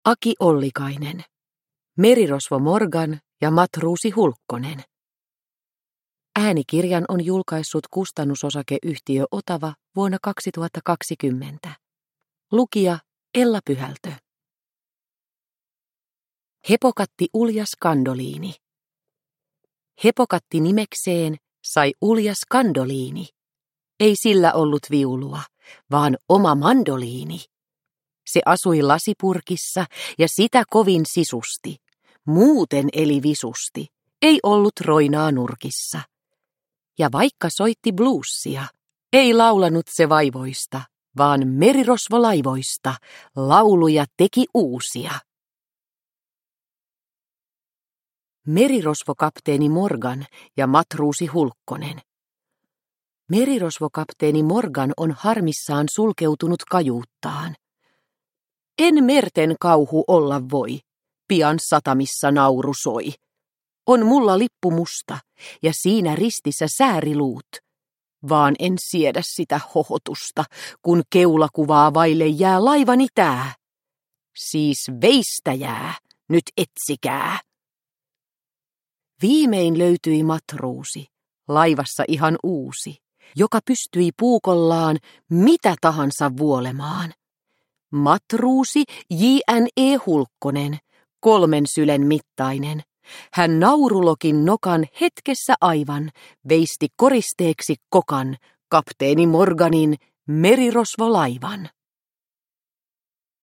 Merirosvo Morgan ja matruusi Hulkkonen – Ljudbok – Laddas ner